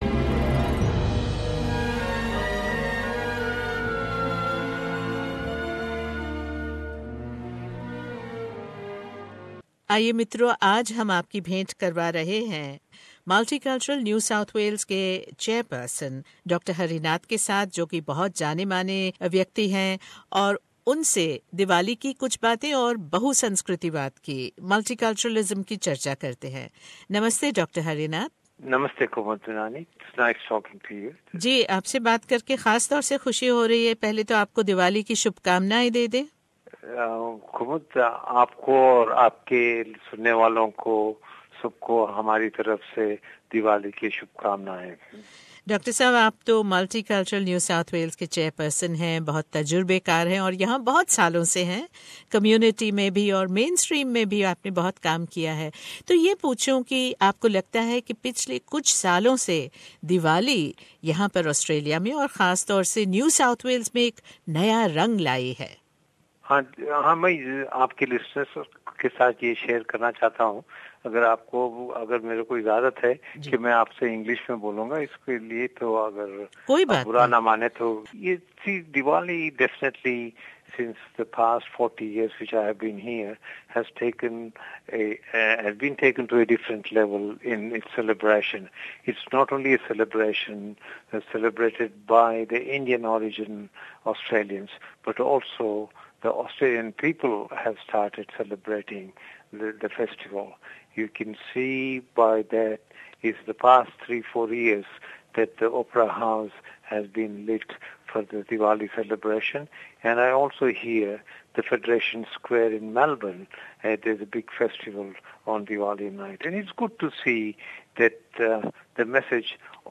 मुल्टीकल्चरल NSW के चेयर पर्सन डॉ हरी हरिनाथ ने दिया SBS हिंदी को विशेष दिवाली सन्देश। उन्होंने चर्चा की है दिवाली की नसब और ऑस्ट्रेलिया भर में जागरूकता के बारे में।